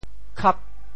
潮州发音 潮州 kab8